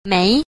b. 眉 – méi – mi